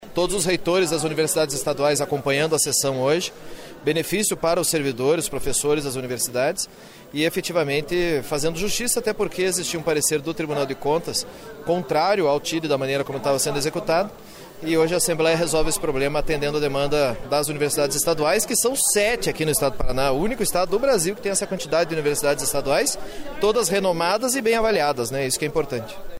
Pedro Lupion fala da importância da aprovação do TIDE como regime de trabalho